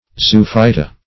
Zoophyta \Zo*oph"y*ta\, n. pl. [NL., from Gr. zw^,on an animal +